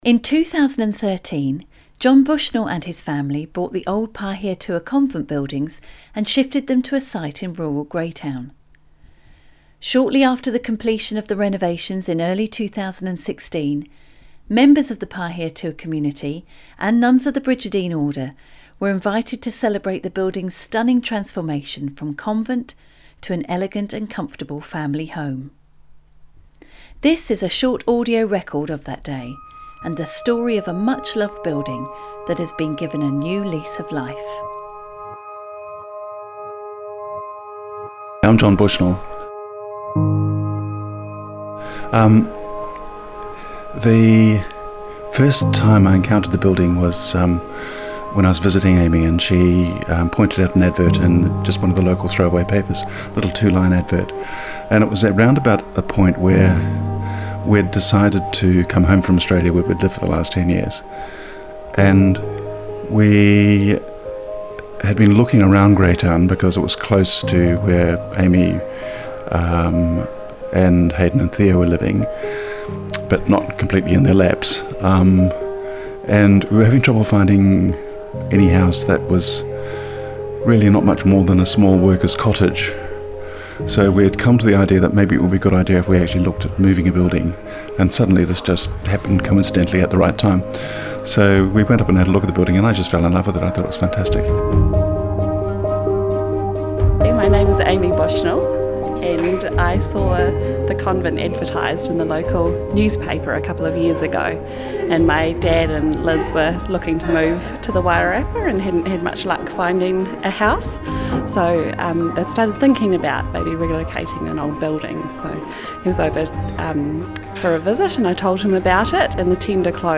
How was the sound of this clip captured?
This is a short audio record of that day, and the story of a much loved building that has been given a new lease of life.